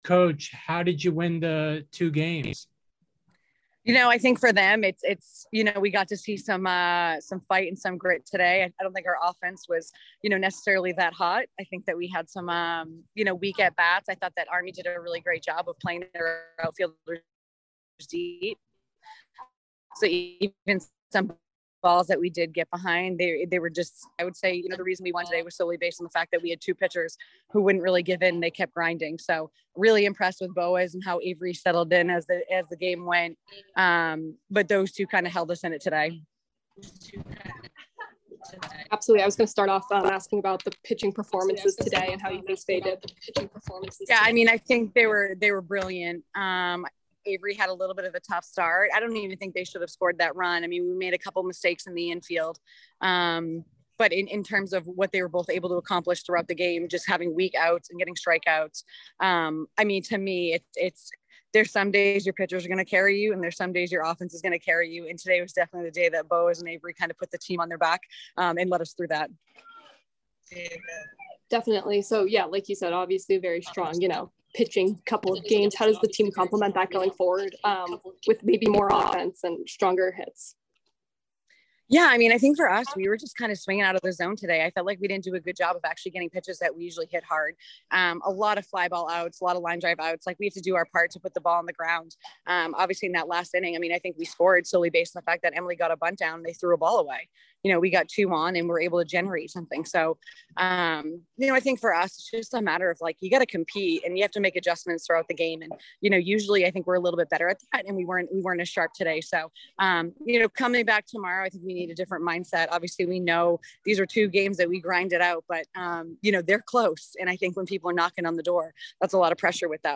Army DH Softball Postgame Interview